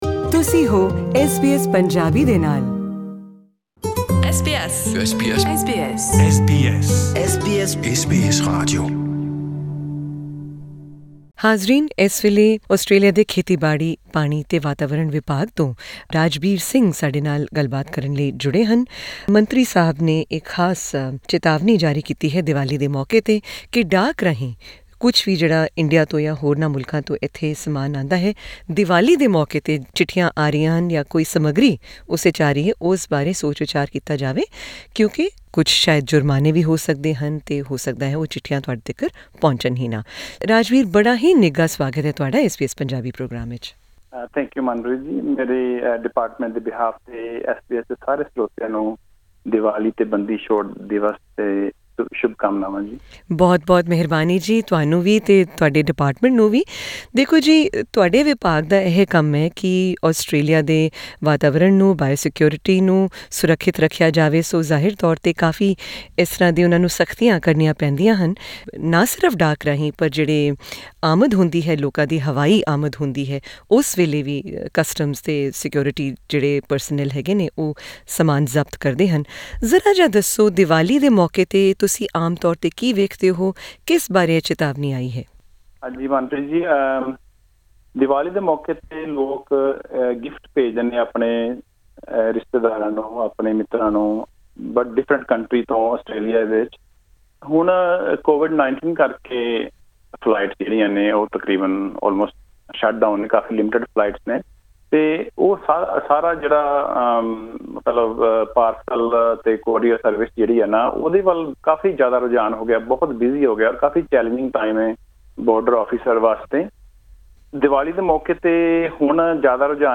ਐਸ ਬੀ ਐਸ ਪੰਜਾਬੀ ਨਾਲ ਇੱਕ ਇੰਟਰਵਿਊ